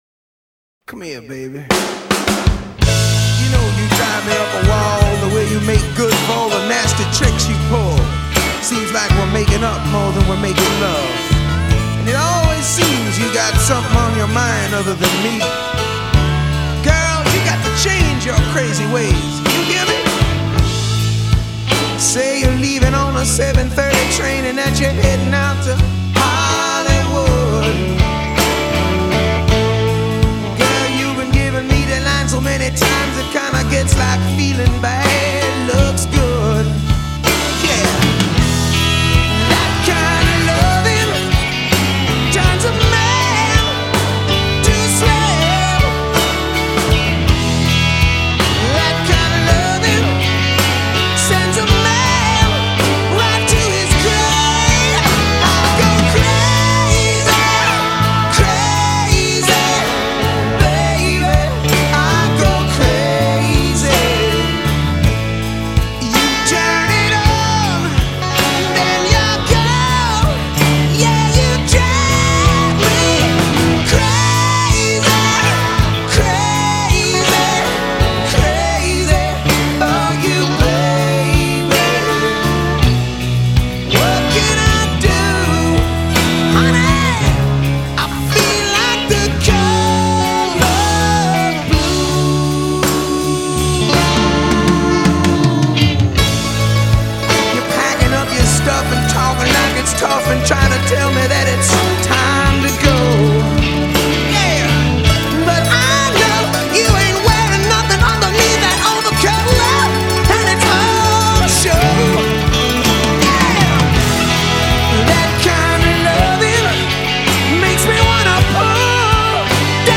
Категория: Красивая музыка » Романтическая музыка